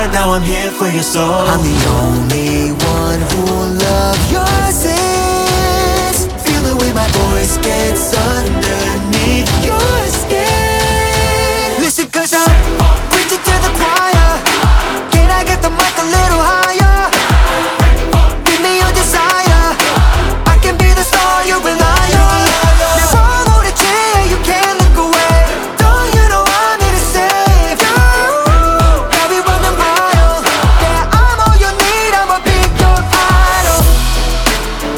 K-Pop Pop
Жанр: Поп музыка